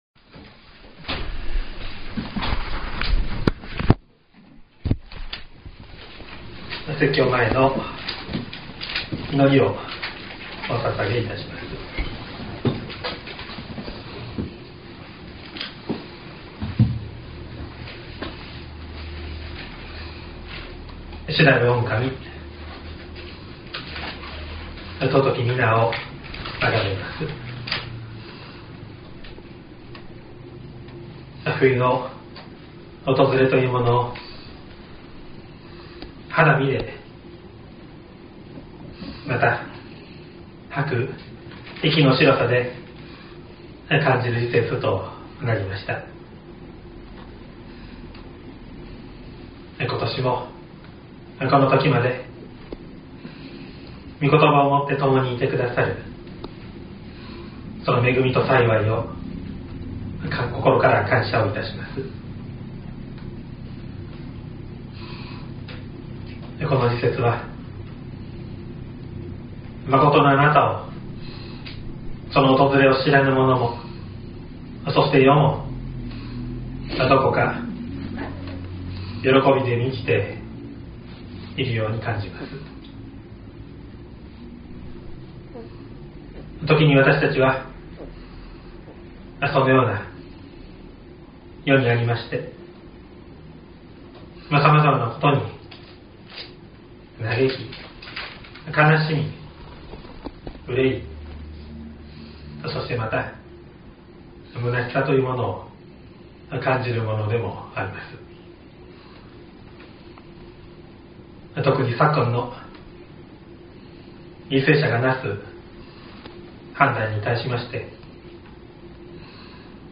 2022年12月18日朝の礼拝「時を満たされる神」西谷教会
音声ファイル 礼拝説教を録音した音声ファイルを公開しています。